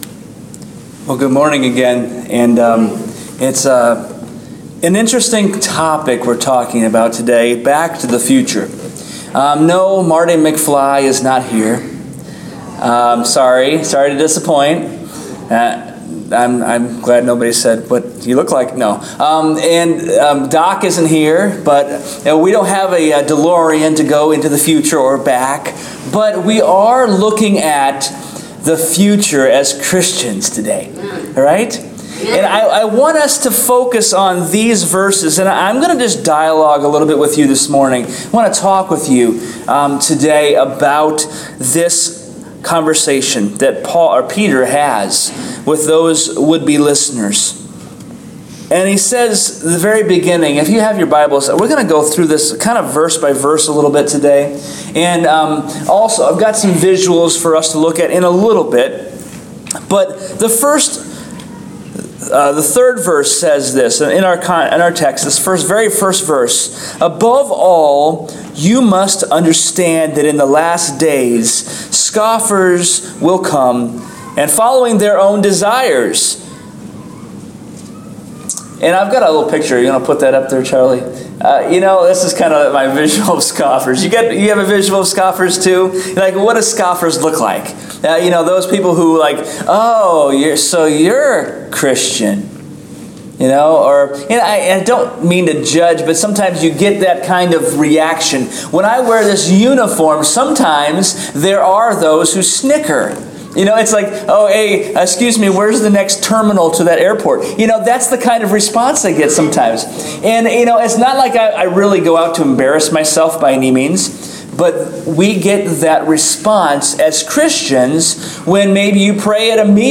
Sermon Podcast: Holiness – Back to the Future